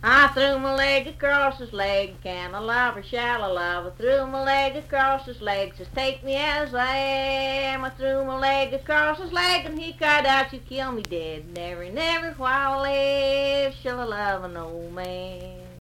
Unaccompanied vocal music
Marriage and Marital Relations, Bawdy Songs, Humor and Nonsense
Voice (sung)